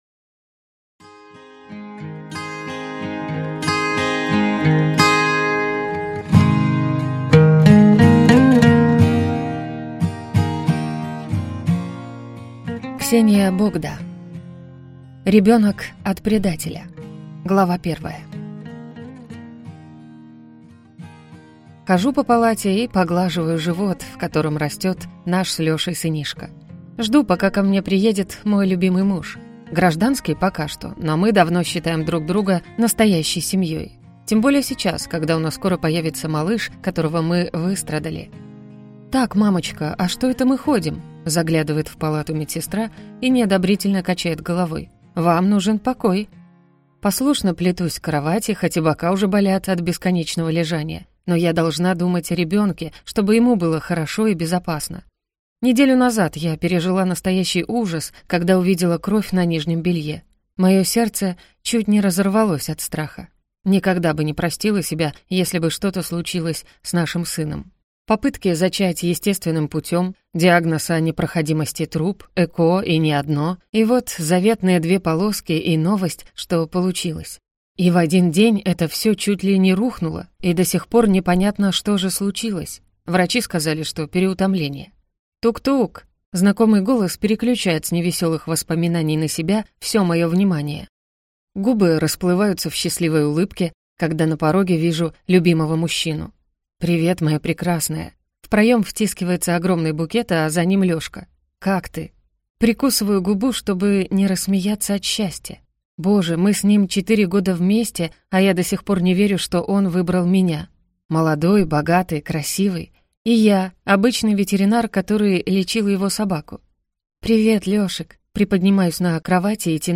Аудиокнига Ребенок от предателя | Библиотека аудиокниг